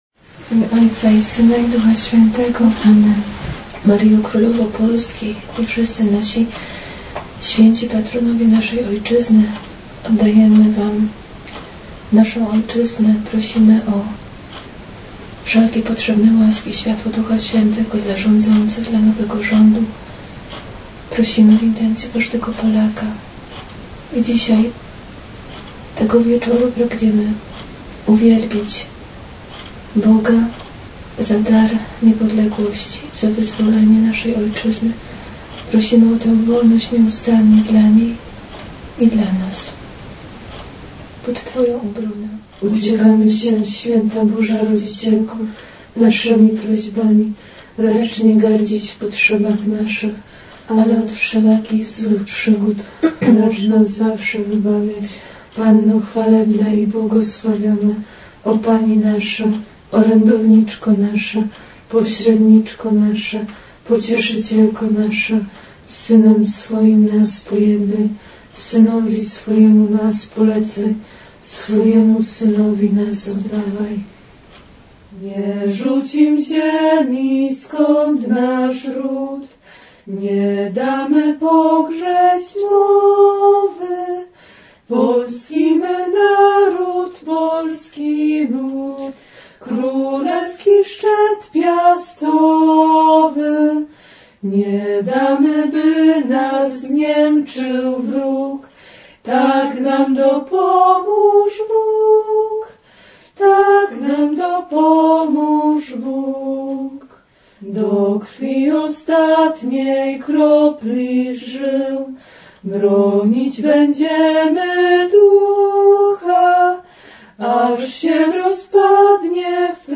W Niepokalanowie 11 listopada 2015 roku, w klasztorze Sióstr, u boku Jasnogórskiej Pani, odbyła się spontaniczna wieczernica patriotyczna.
Nie zabrakło podczas tego wieczorku siostrzanej modlitwy za Ojczyznę, a także odśpiewania Apelu Jasnogórskiego – Tej, Która ma Polskę pod czułą opieką.